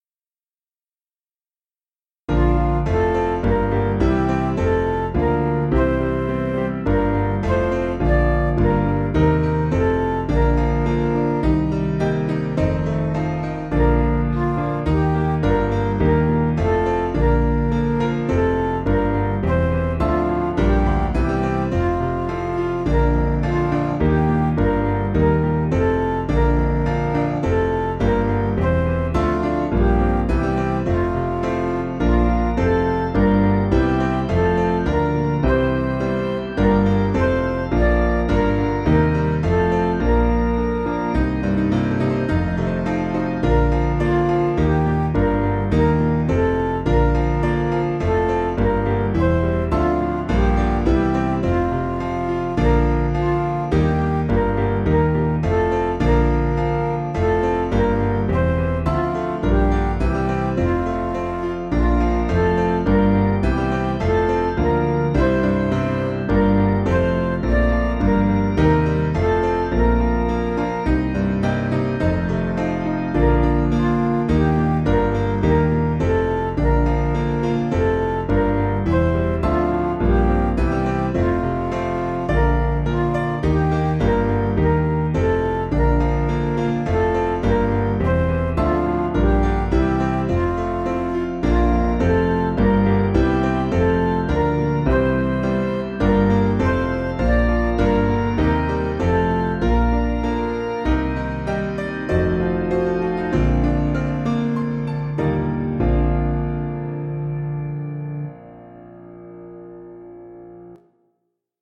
Piano & Instrumental
(CM)   3/Bb
Midi